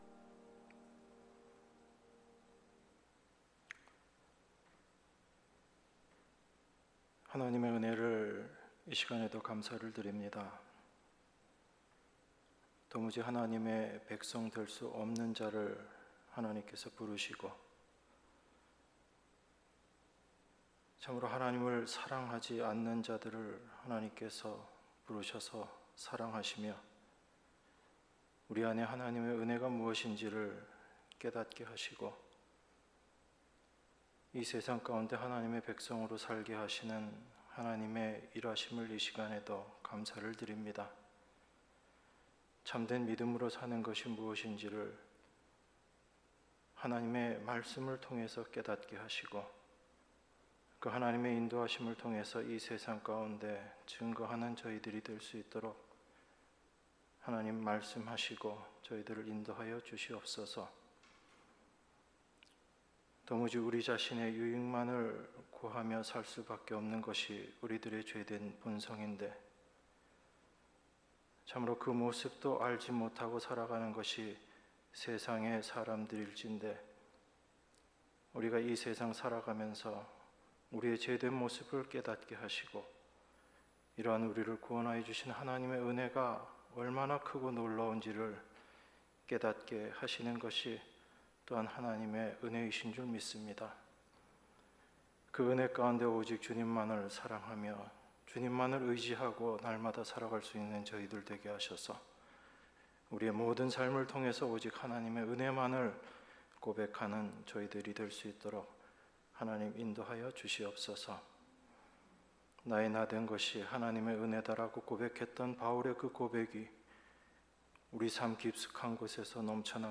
수요예배 출애굽기 20장 14절